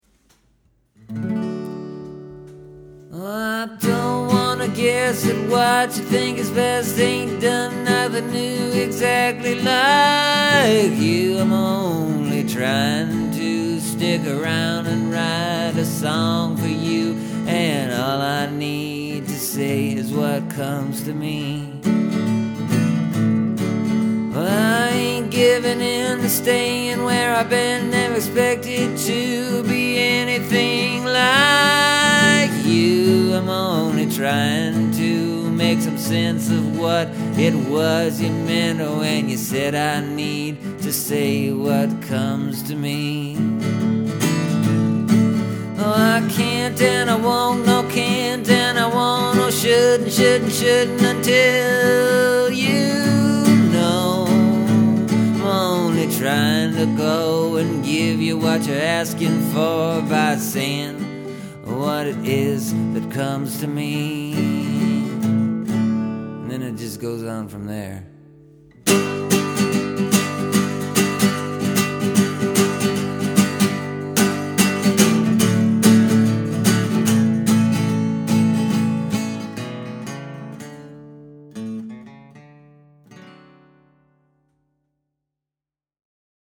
The music is completely different. Sounds like a a pop tune from a Romantic Comedy movie or something.
It’s more or less a love song now.